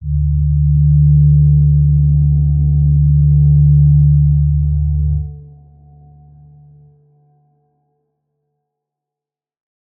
G_Crystal-D3-f.wav